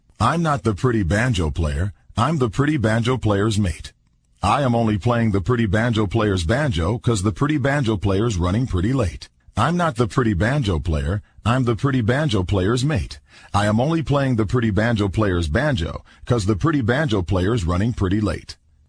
tongue_twister_02_01.mp3